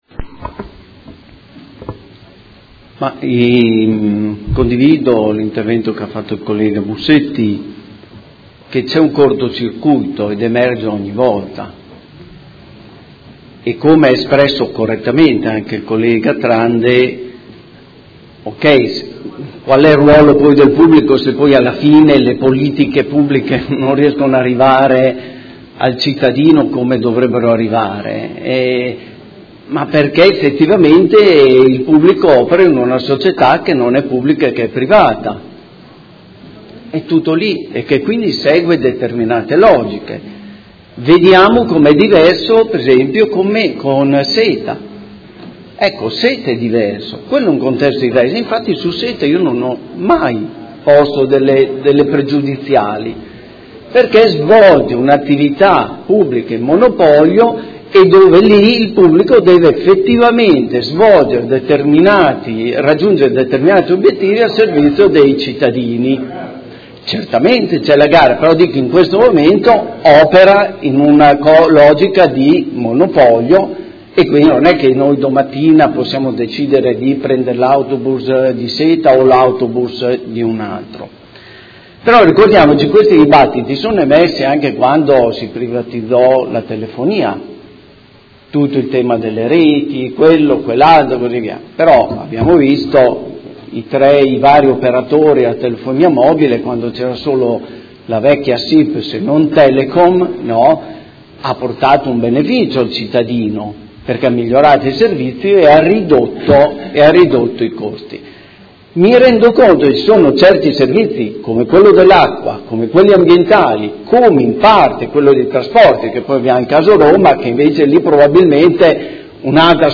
Seduta del 5/04/2018. Dichiarazioni di voto su proposta di deliberazione: Conclusione di Patti parasociali fra i Soci pubblici e fra i Soci pubblici di Area modenese di HERA S.p.A.